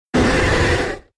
Cri de Mackogneur K.O. dans Pokémon X et Y.